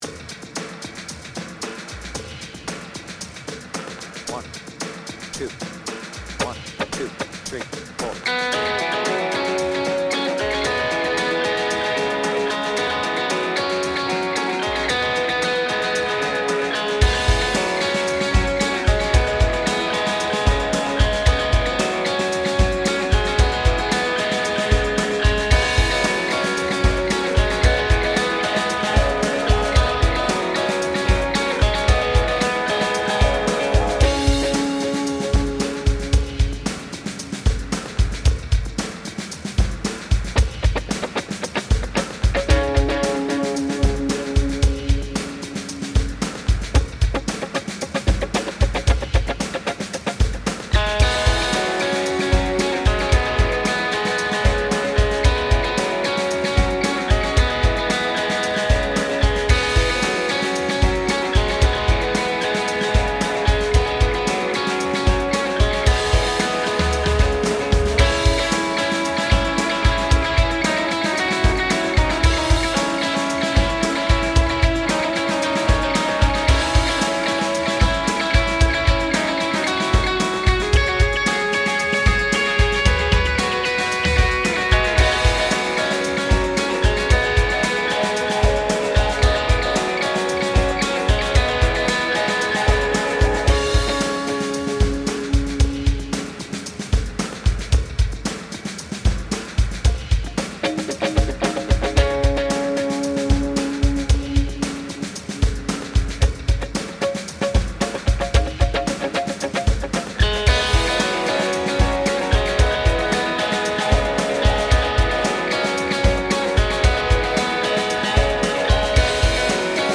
BPM : 113
Tuning : Eb
Without vocals